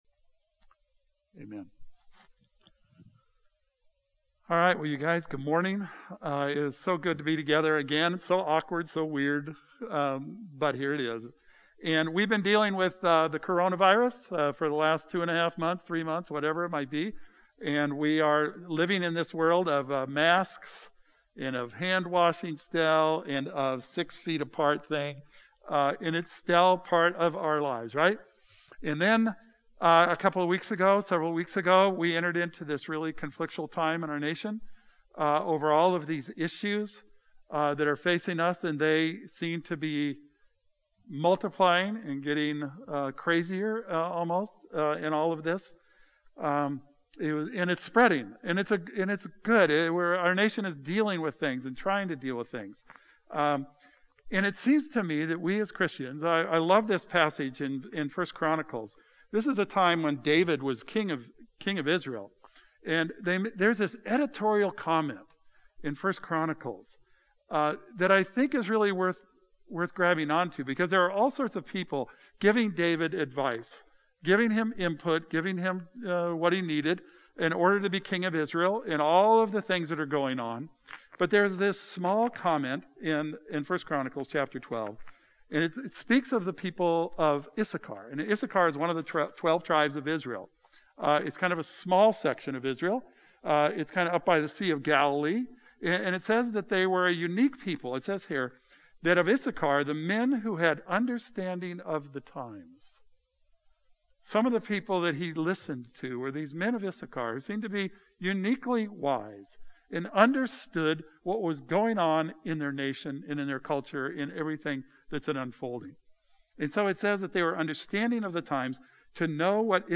Sunday Service: June 14th, 2020